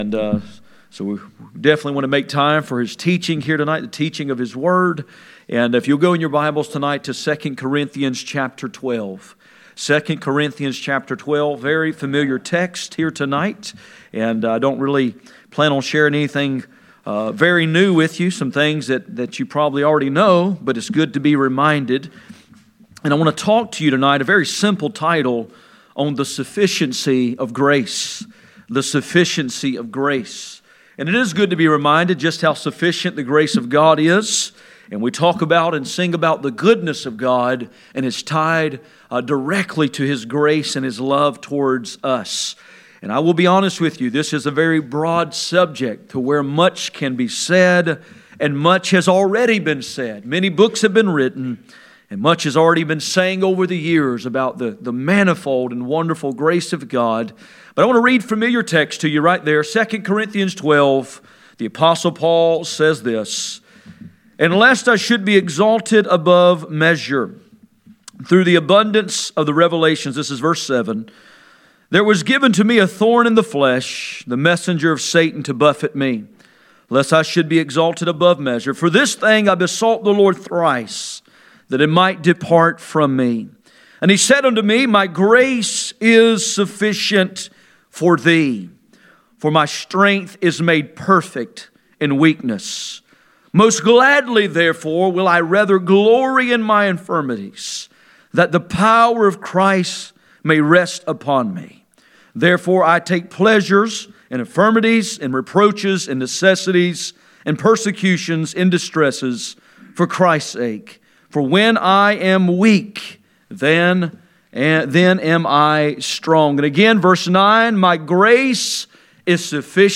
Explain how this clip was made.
Passage: 2 Corinthians 12:7-12 Service Type: Sunday Evening